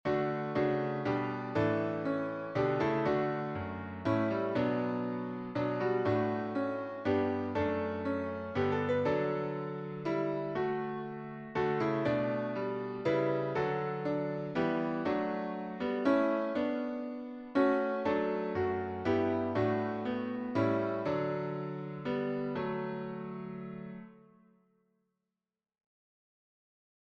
Irish melody